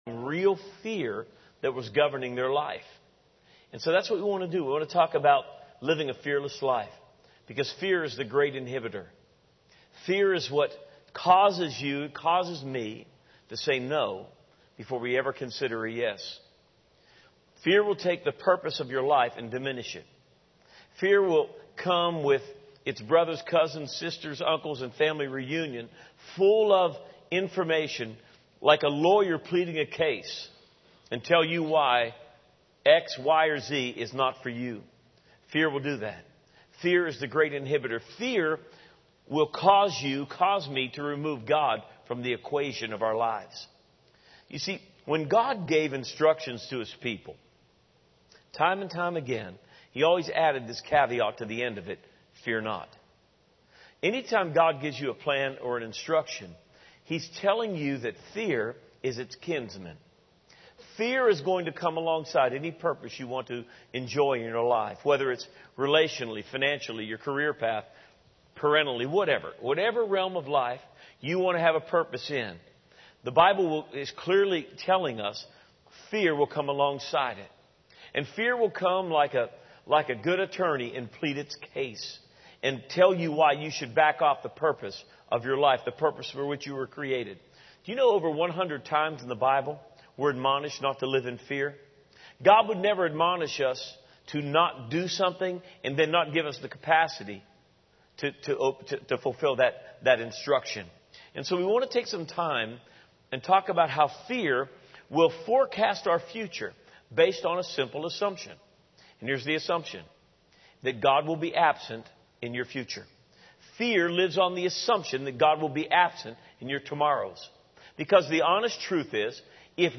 Access sermon video, audio, and notes from Victory Family Church online today!